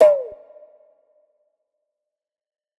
JAMBOXXL_DS2_Perc.wav